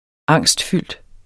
Udtale [ -ˌfylˀd ] Betydninger fuld af angst